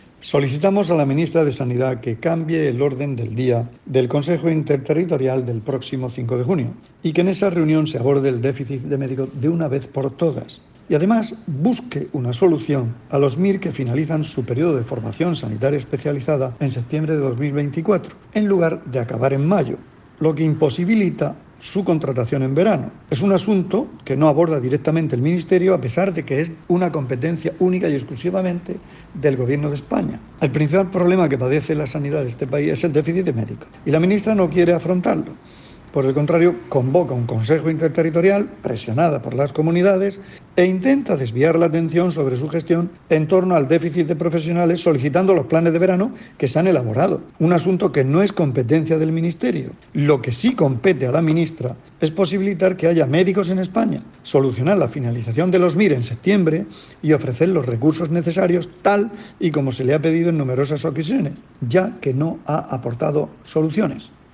Declaraciones el consejero de Salud, Juan José Pedreño, sobre la petición de la Región ante el próximo Consejo Interterritorial